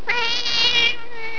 Memorial Page Full of Phaedra Phaedra Vital Statistics Birthday - approx 4/1/90 Gotcha Day - approx 6/5/90 Death Date - 10/26/2001 Weight - 6.5 lbs Distinguishing Characteristics - Classic tabby with a Siamese voice!
Click on Phaedra's face to hear her roar!